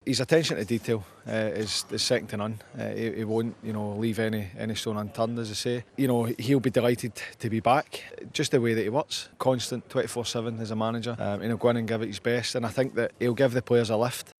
McFadden tells Sky Sports News, Moyes will get the club firing again.